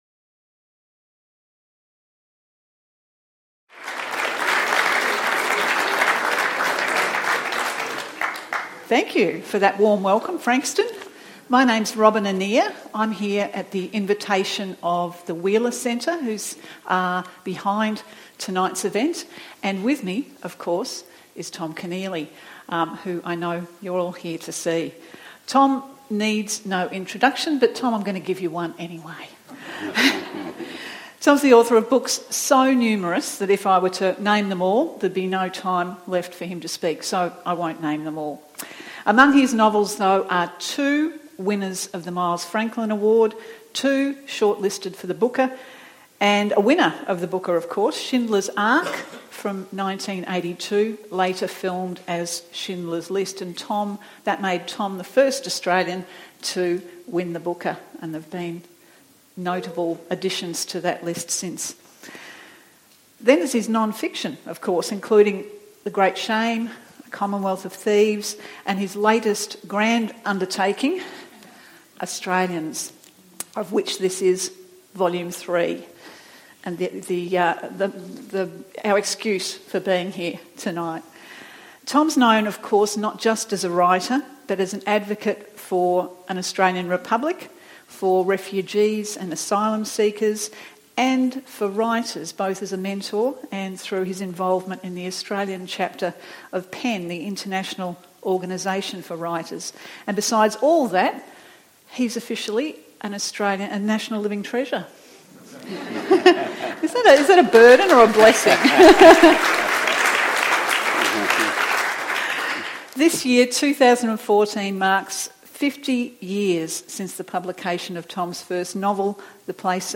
Join national treasure and literary icon Thomas Keneally as he discusses his work, past and present